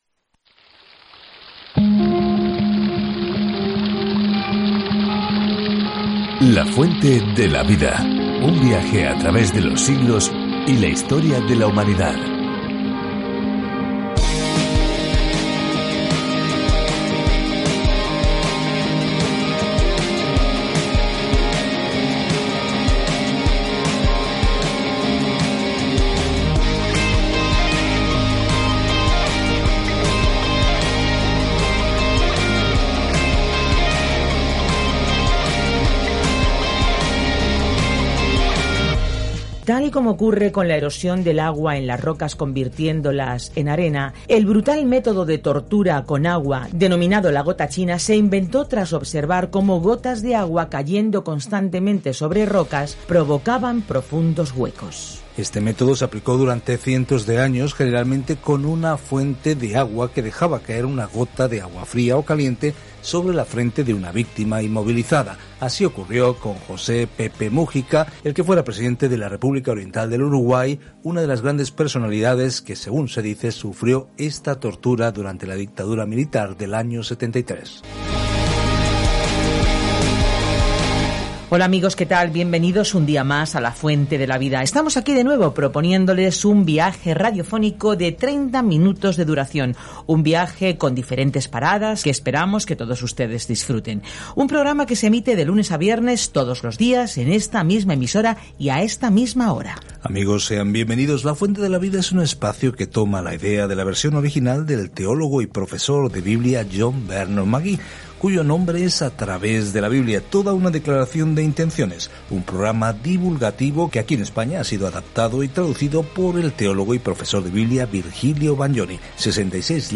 Escritura DANIEL 1:14-21 DANIEL 2:1 Día 2 Iniciar plan Día 4 Acerca de este Plan El libro de Daniel es a la vez una biografía de un hombre que creyó en Dios y una visión profética de quién eventualmente gobernará el mundo. Viaja diariamente a través de Daniel mientras escuchas el estudio de audio y lees versículos seleccionados de la palabra de Dios.